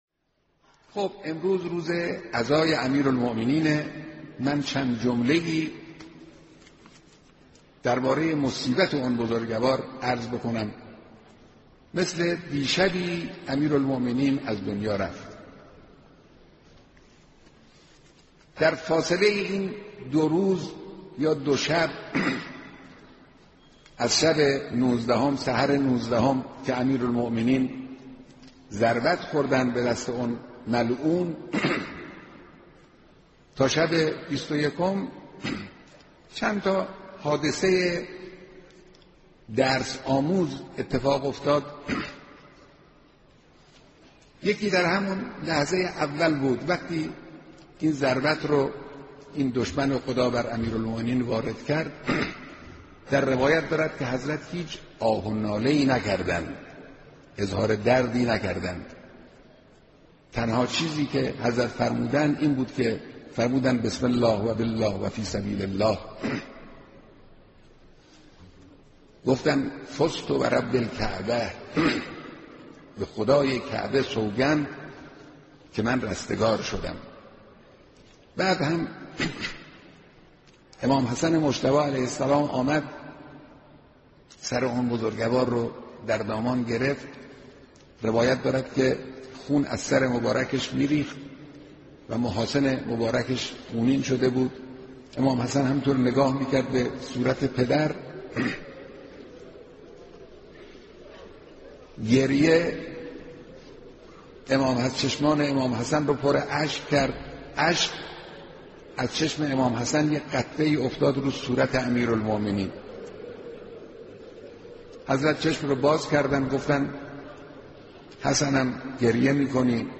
صوت کامل بیانات
ذکر مصائب امیرالمومنین در نماز جمعه تهران